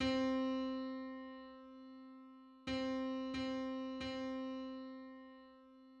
In music and tuning, the ragisma is an interval with the ratio of 4375:4374,[1] ≈0.396 cents (a superparticular ratio).
Ragisma_on_C.mid.mp3